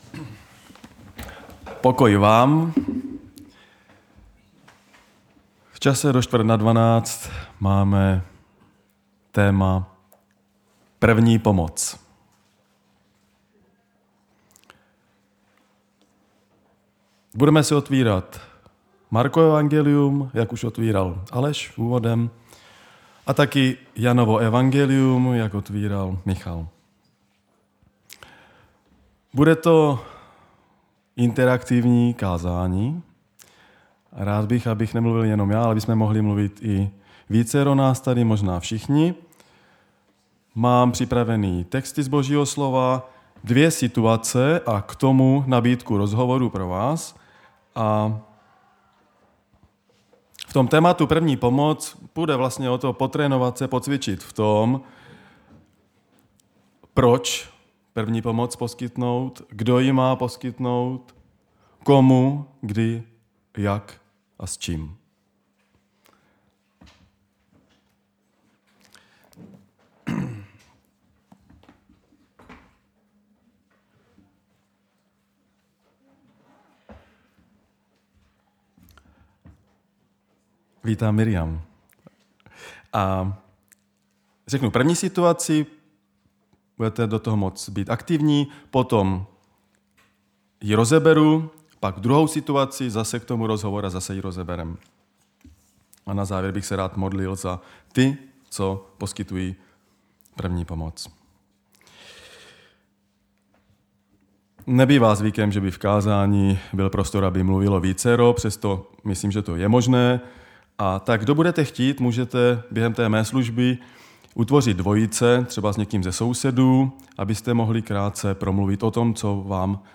Nedělní vyučování
Záznamy z bohoslužeb